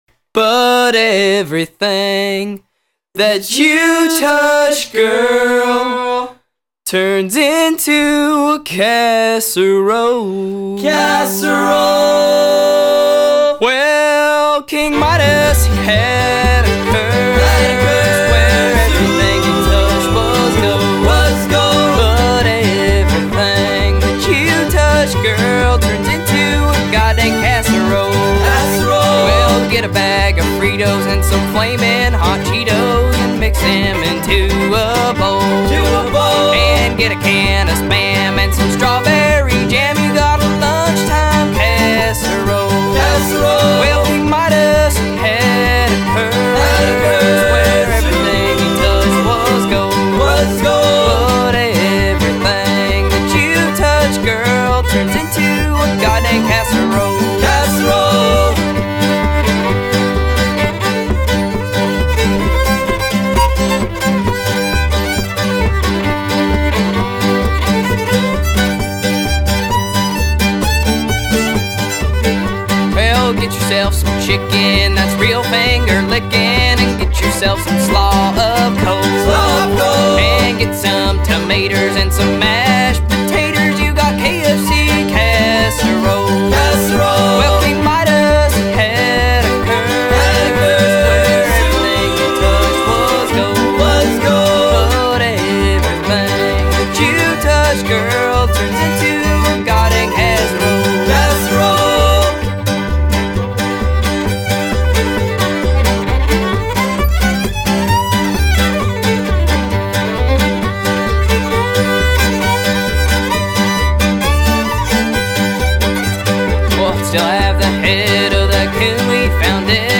Mandolin/Lead Vocals
Fiddle/Vocals
Upright Bass
Percussion
Guitar/Vocals